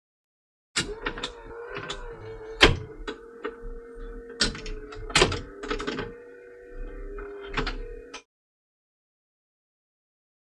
Juke Box; Juke Box Mechanism. Good Sound.